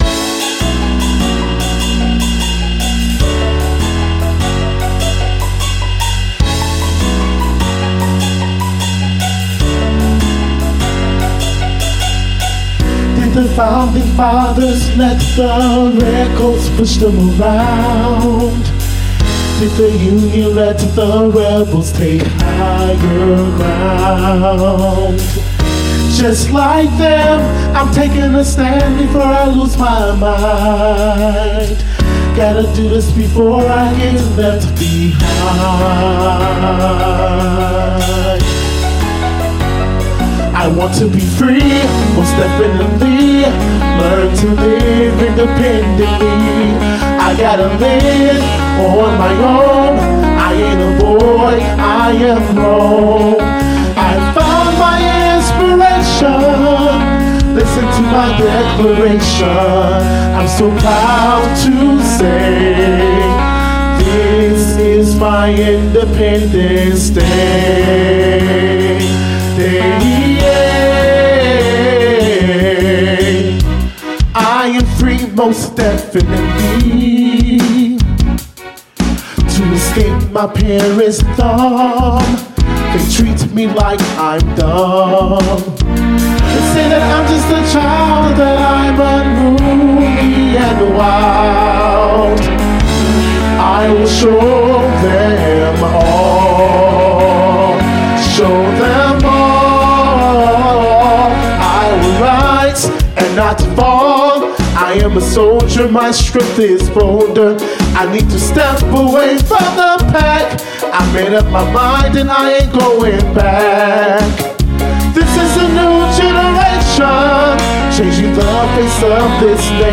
Accompaniment Track